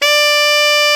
Index of /90_sSampleCDs/Roland L-CD702/VOL-2/SAX_Alto Short/SAX_A.ff 414 Sh
SAX A.FF D0H.wav